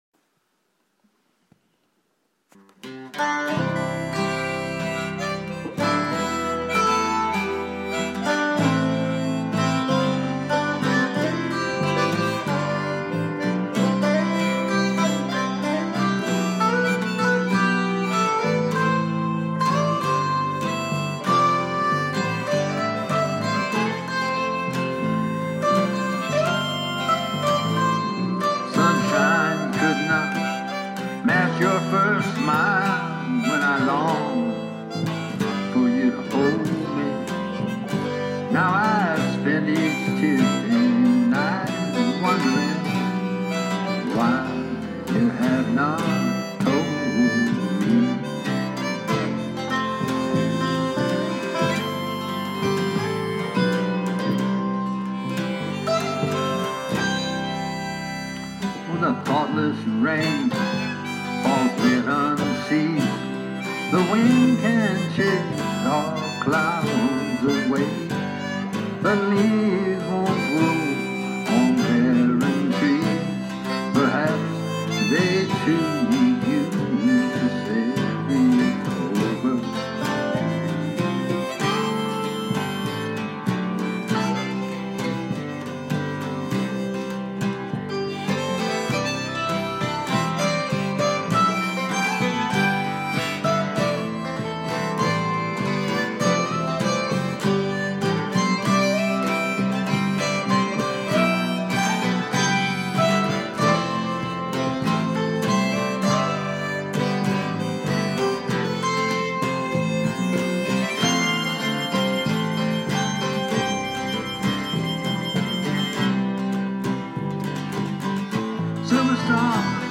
So haunting and beautiful!